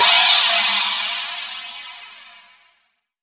Index of /90_sSampleCDs/AKAI S6000 CD-ROM - Volume 3/Crash_Cymbal1/FX_CYMBAL
EX-MIX CYM S.WAV